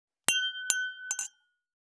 296シャンパングラス,ワイングラス乾杯,イタリアン,バル,フレンチ,
コップ効果音厨房/台所/レストラン/kitchen食器